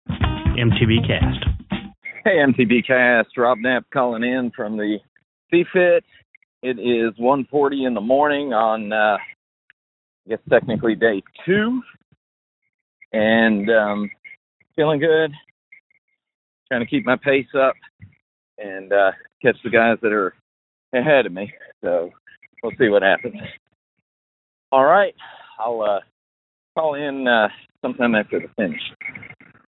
called in from near Ocala!